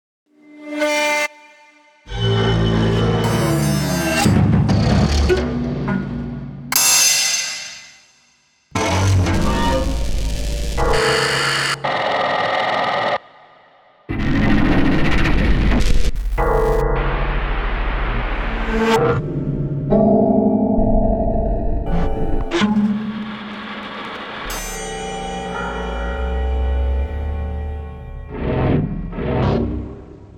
Sie basiert zudem auf der Analyse des »Klangraums« Berlin in den Jahren 1963–1965 – unter Einbeziehung von Nachrichtenarchiven – sowie auf Experimenten, in denen die vokalen Intonationen Emilio Vedovas mittels neuronaler Netzwerke in Klang übersetzt werden.